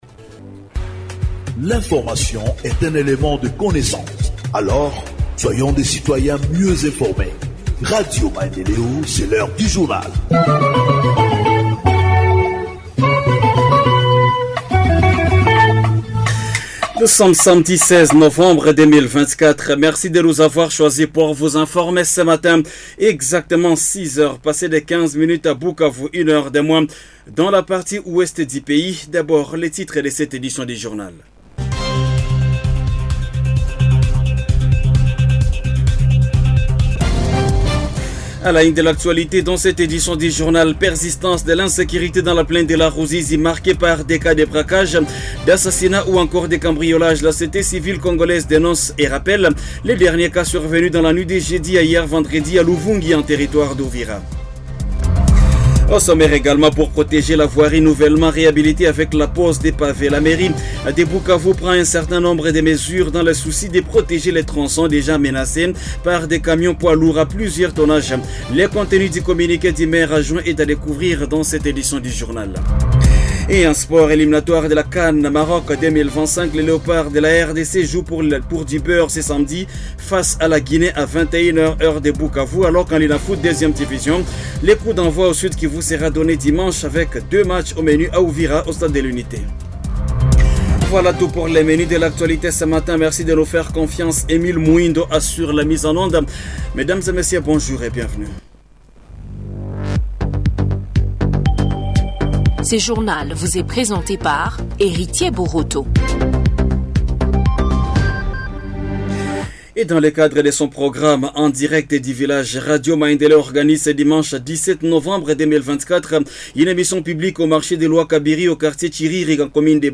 Journal Francais du 16 novembre 2024 – Radio Maendeleo